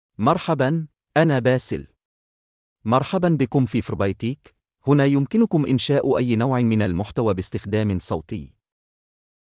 Bassel — Male Arabic (Iraq) AI Voice | TTS, Voice Cloning & Video | Verbatik AI
Bassel is a male AI voice for Arabic (Iraq).
Voice sample
Listen to Bassel's male Arabic voice.
Male
Bassel delivers clear pronunciation with authentic Iraq Arabic intonation, making your content sound professionally produced.